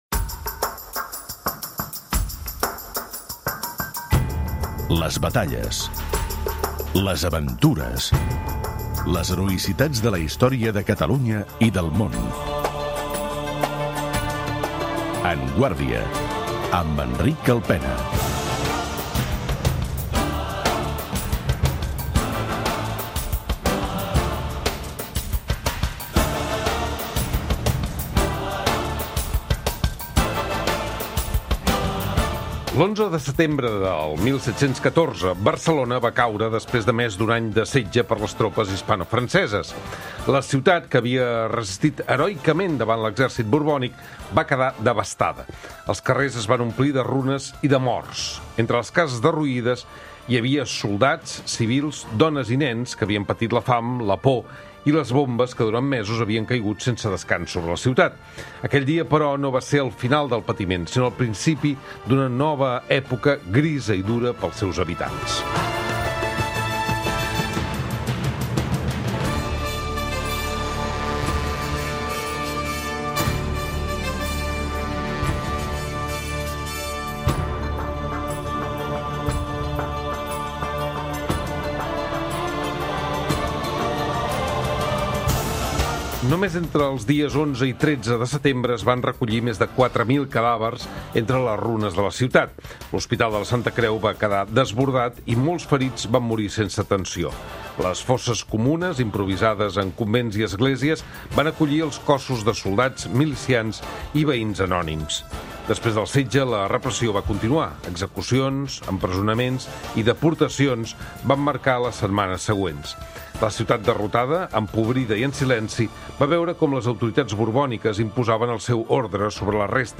Aquell dia, però, no va ser el final del patiment, sinó el principi d'una nova època grisa i dura per als seus habitants. En parlem amb l'historiador